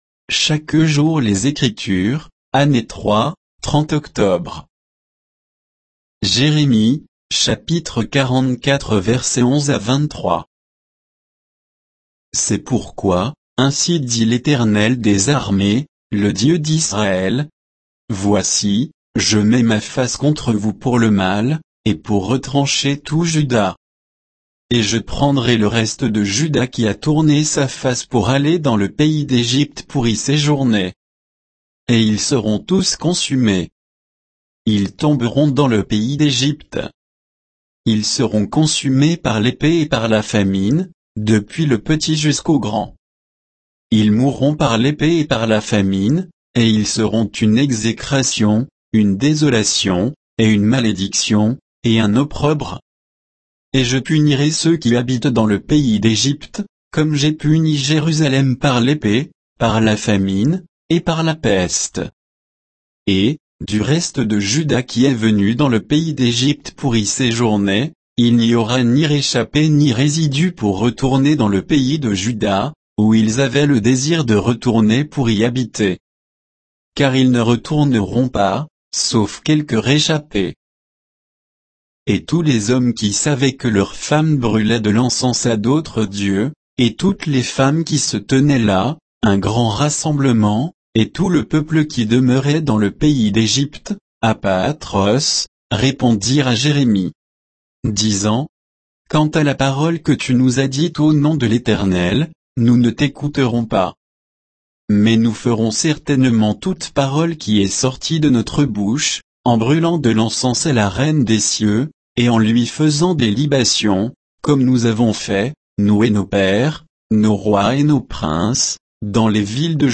Méditation quoditienne de Chaque jour les Écritures sur Jérémie 44